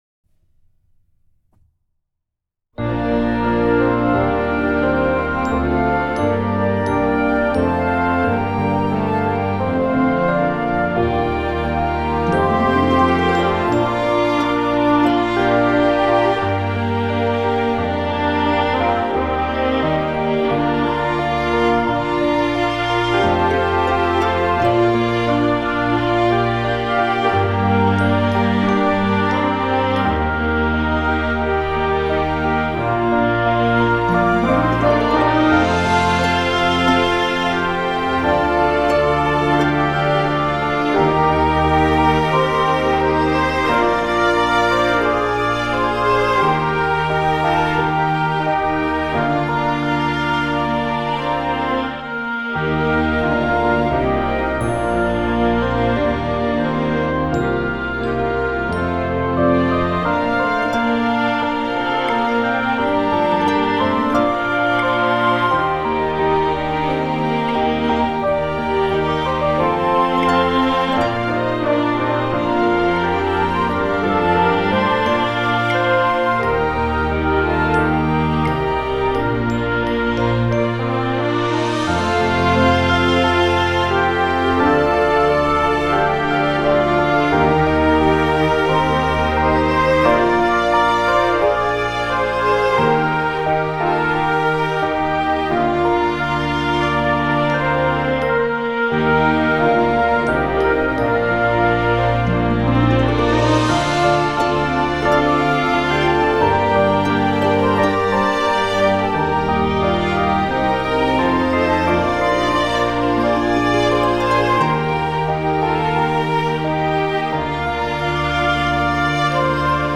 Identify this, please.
Voicing: Perc.